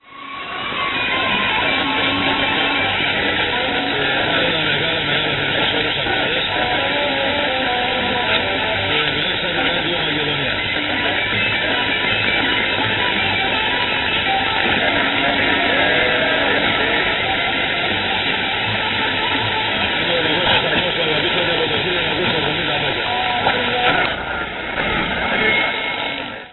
1385 kHz - Radio Makedonia, Greece on empty frequency.
Own jingles! 22.52- / O=0-2 -